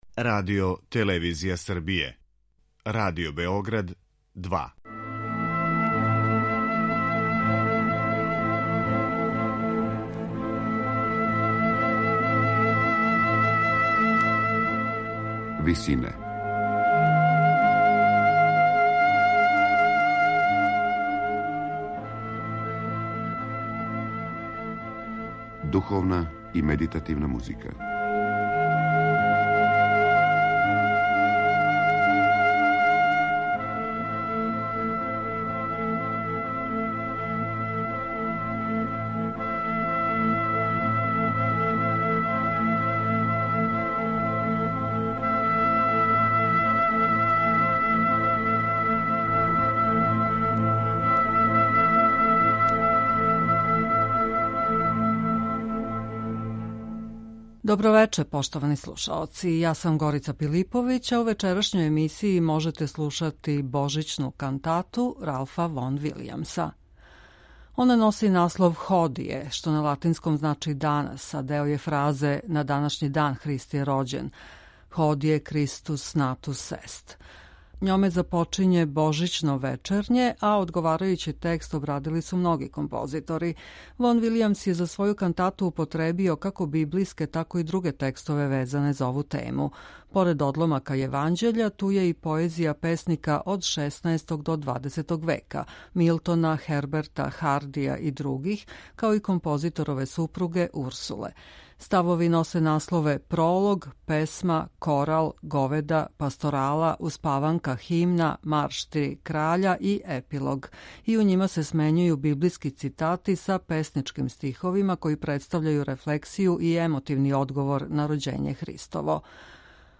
Божићнa кантатa Ралфа Вон-Вилијамса